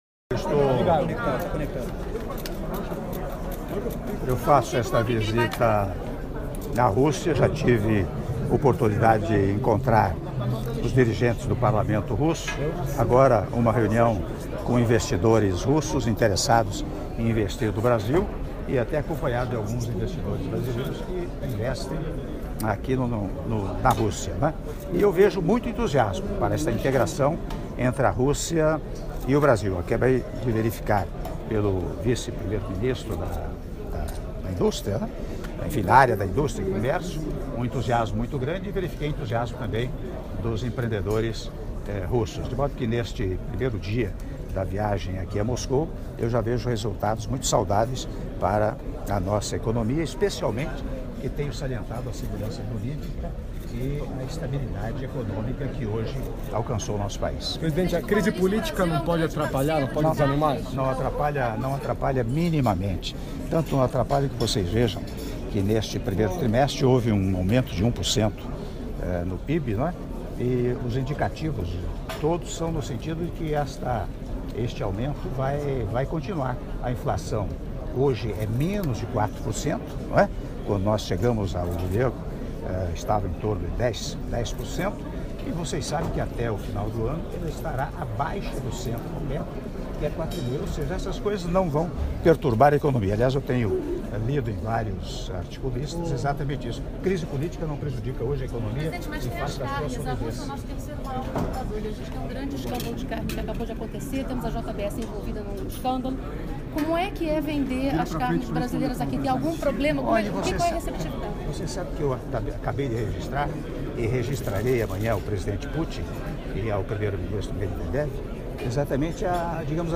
Áudio da entrevista coletiva concedida pelo Presidente da República, Michel Temer, após Seminário de Captação de Investimentos Russos no Brasil (02min30s)